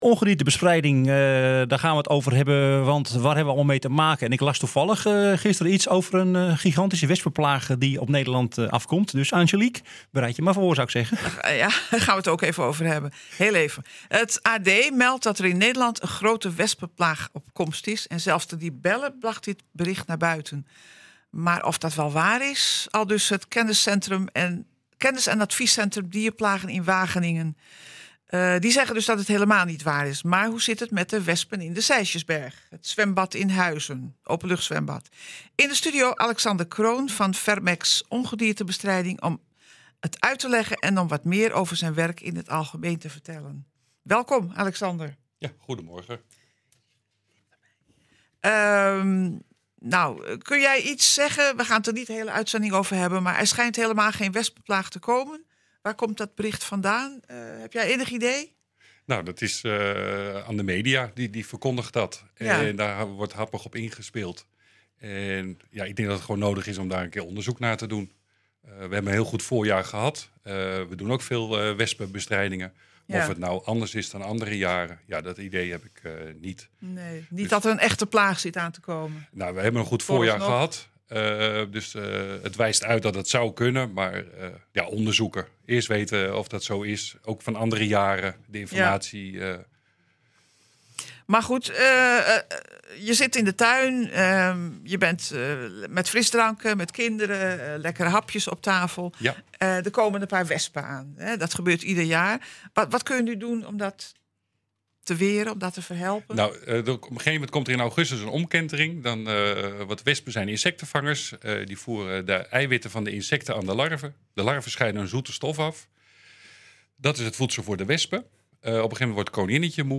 Het AD meldt dat er in Nederland een grote Wespenplaag op komst is en zelfs de Libelle bracht dit bericht naar buiten. Maar dat is helemaal niet waar, aldus het Kennis en Adviescentrum Dierplagen in Wageningen, maar hoe zit het dan met de wespen in de Sijsjesberg? In de studio